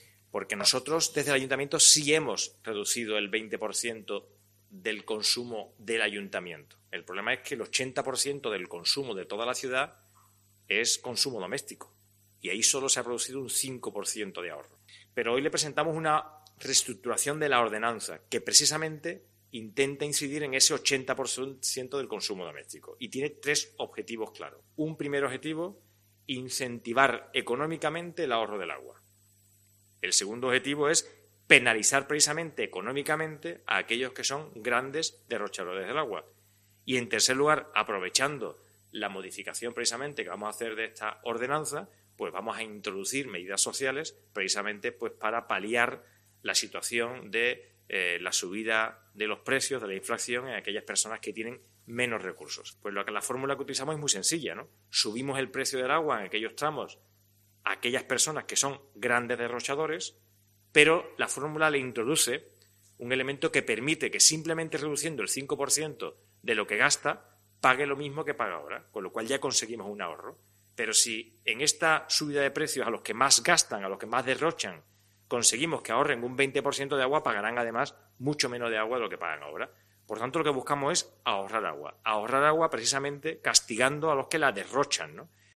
Fernando López Gil, primer teniente de alcaldesa de San Fernando, sobre el ahorro del agua